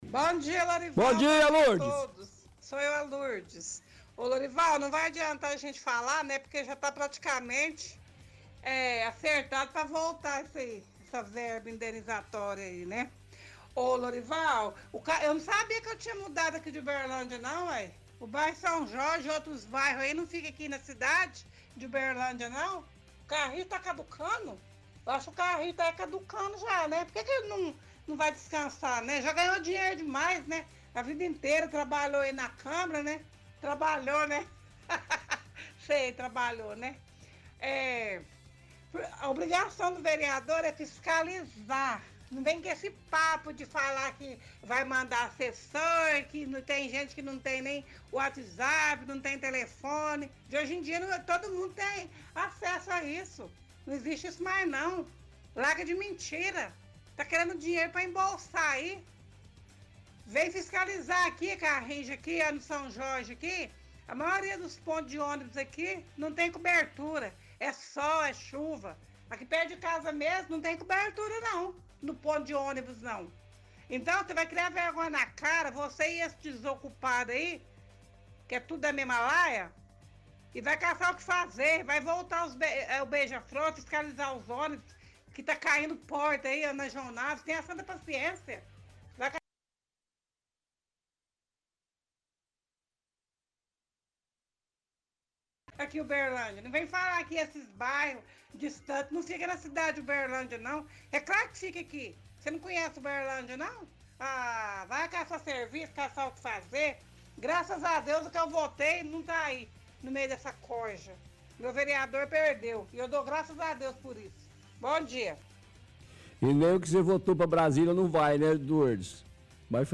– Ouvinte critica fala do vereador Carrijo de volta de verba indenizatória e de que alguns bairros são afastados.